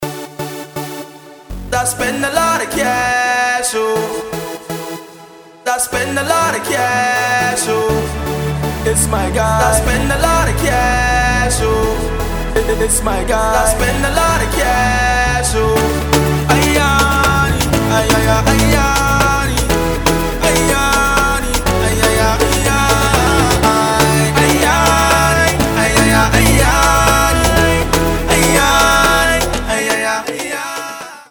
uptempo Afro-Dance meets Pop and Naija Hip-Hop piece